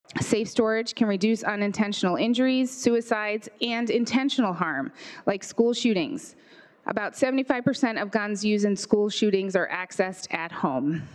In the House Gun Violence Prevention Committee on Wednesday, House Sponsor State Representative Maura Hirschauer said the measure seeks to reduce harm.